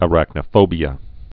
(ə-răknə-fōbē-ə, -nō-)